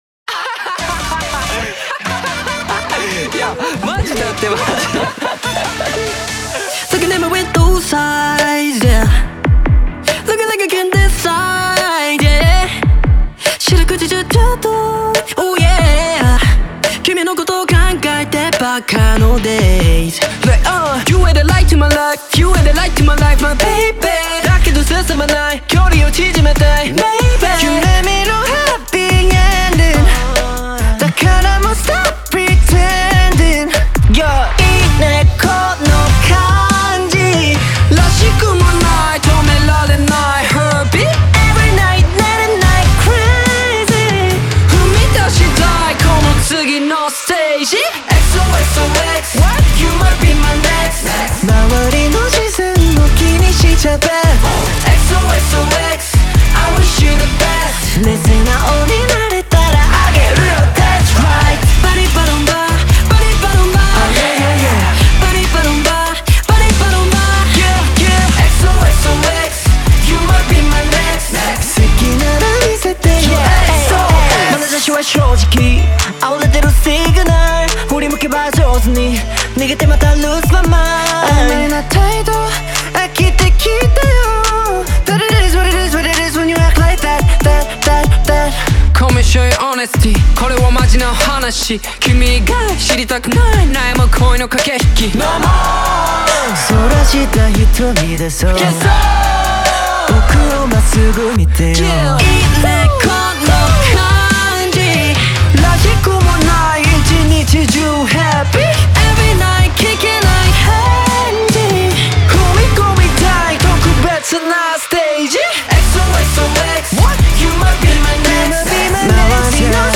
Азиатские хиты